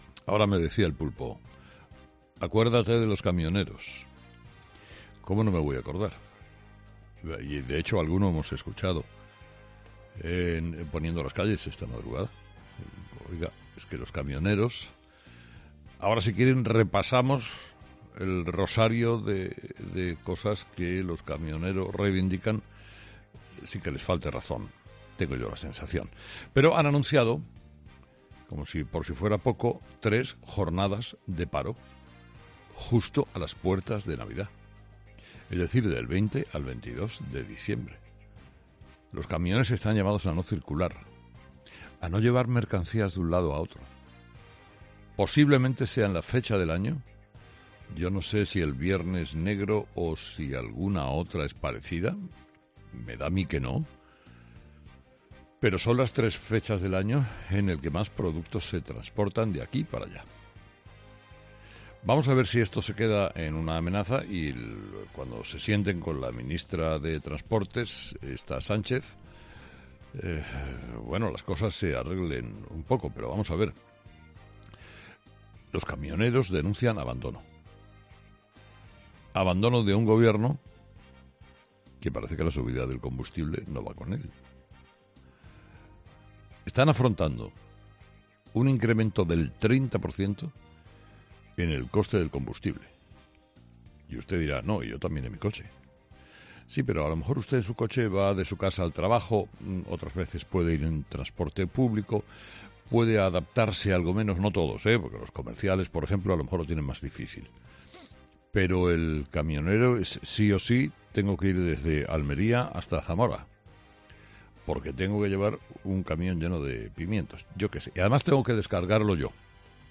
Carlos Herrera, director y presentador de 'Herrera en COPE', ha comenzado el programa de este jueves analizando las principales claves de la jornada, que pasan, entre otros asuntos, por la situación de la pandemia en nuestro país y también por el anuncio de una huelga en el sector del transporte para los días previos al inicio de las fechas navideñas.